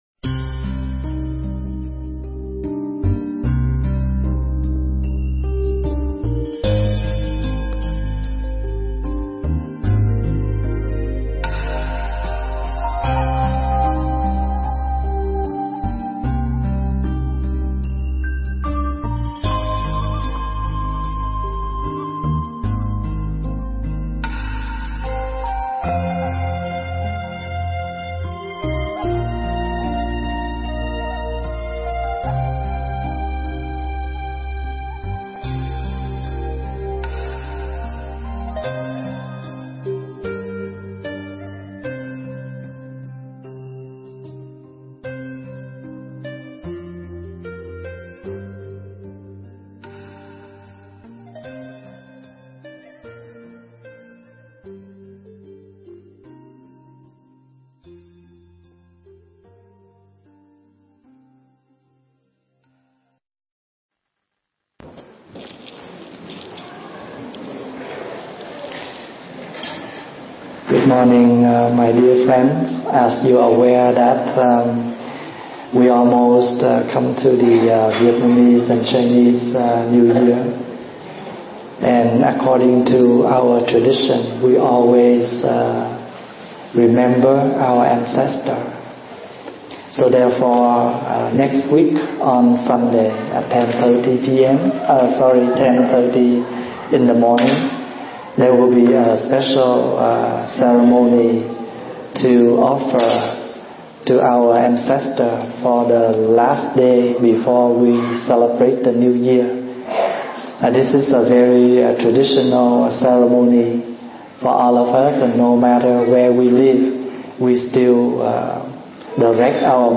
thuyết giảng tại Tu Viện Trúc Lâm, Canada